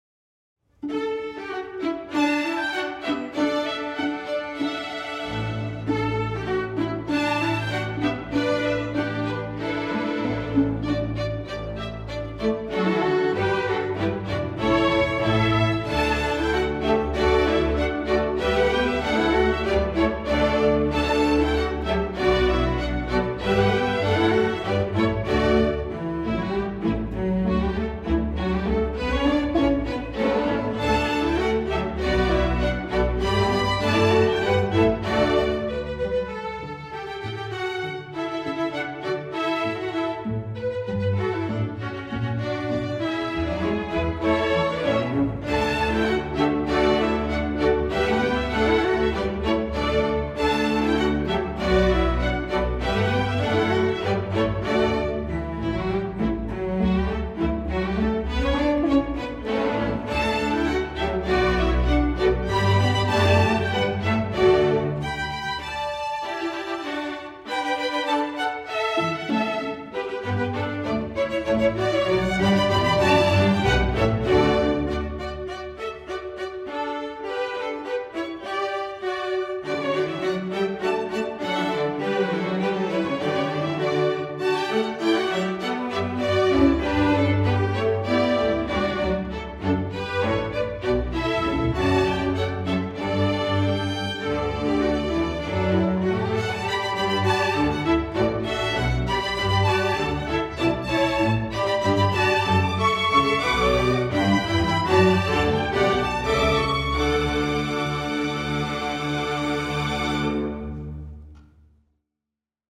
a Christmastime romp straight outta Broadway
String Orchestra